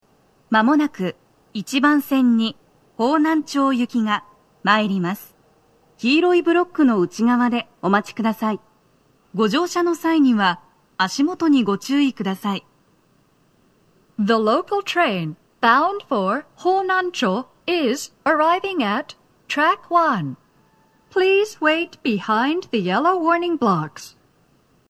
女声
接近放送3
TOA天井型()での収録です。